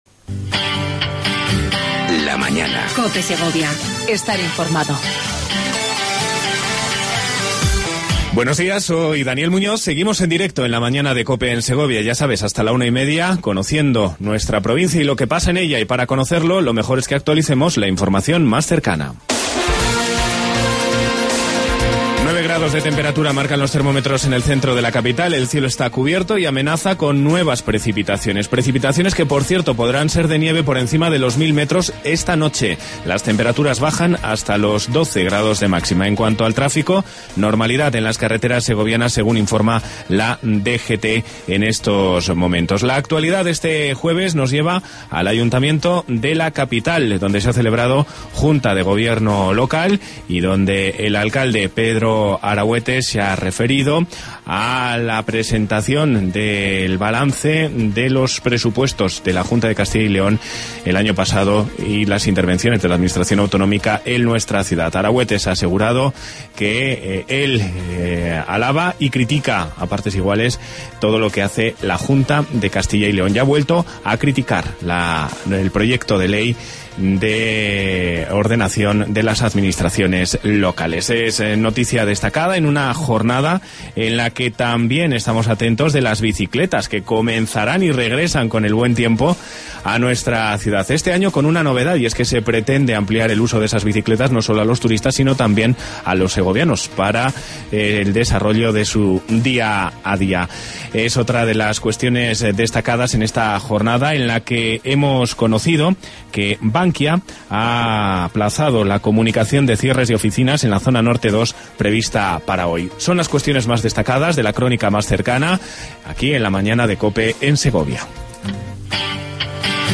Entrevista a Mariano Moreno, alcalde de Lastras Del Pozo.